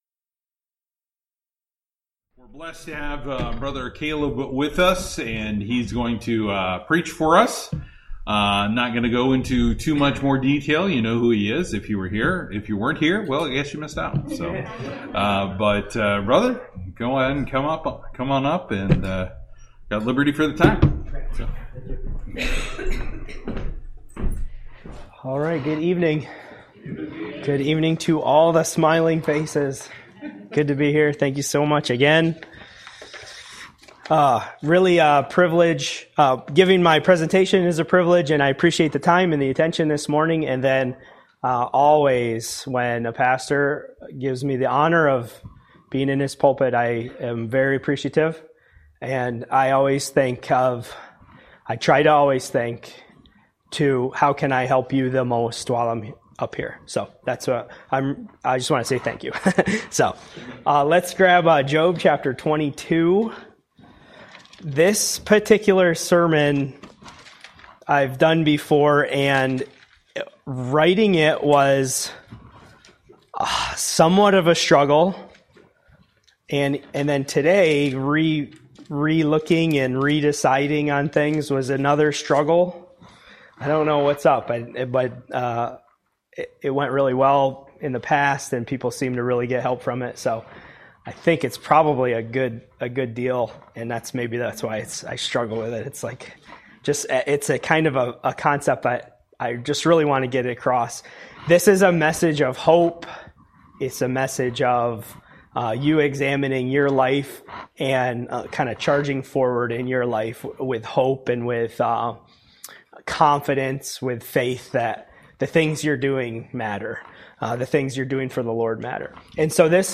Service: Wednesday Night